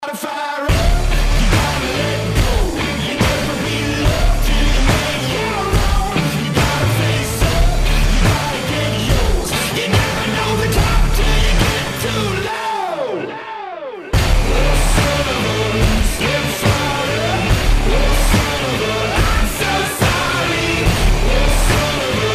• Качество: 181, Stereo
гитара
мужской вокал
рок